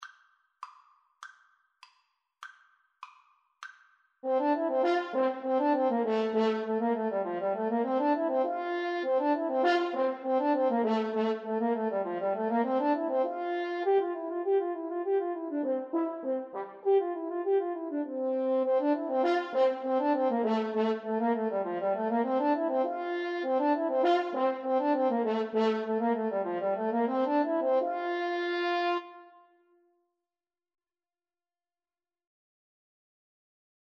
Allegro (View more music marked Allegro)
2/4 (View more 2/4 Music)
Classical (View more Classical French Horn Duet Music)